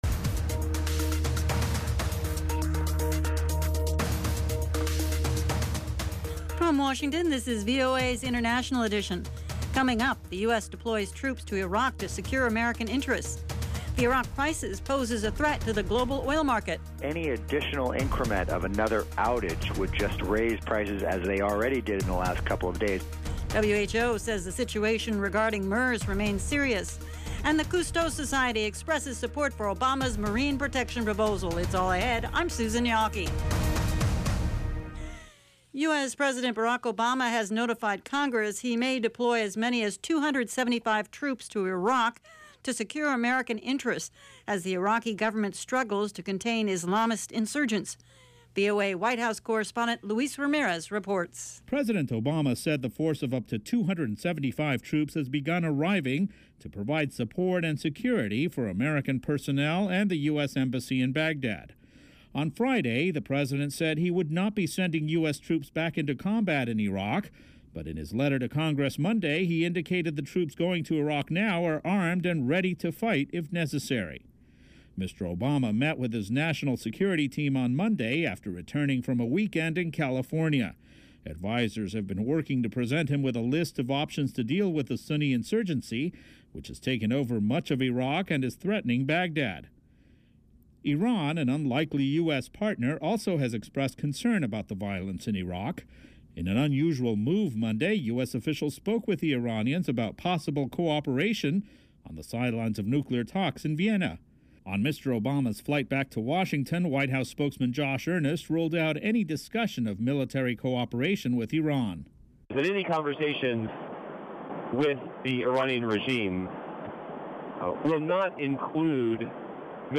International Edition gives you 30 minutes of in-depth world news reported by VOA’s worldwide corps of correspondents - on the events people are talking about.